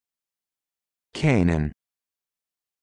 Audio file of the word "Qanun"
How-to-Pronounce-Qanun.mp3